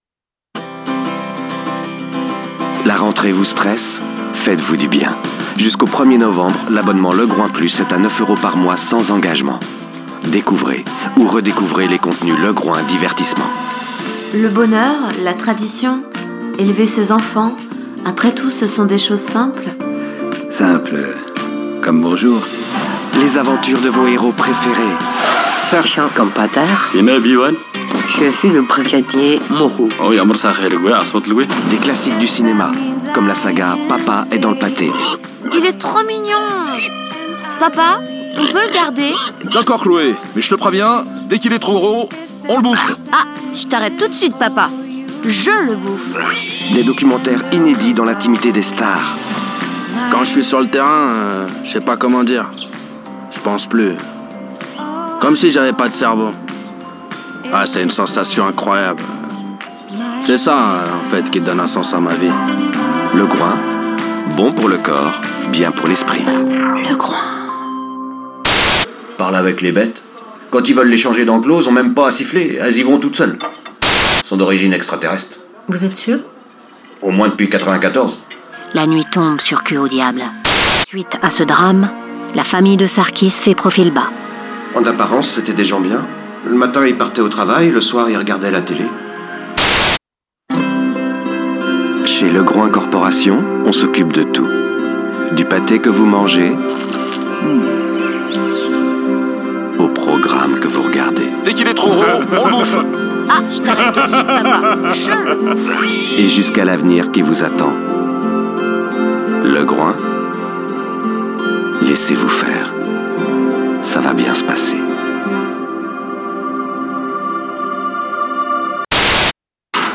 pub
- Baryton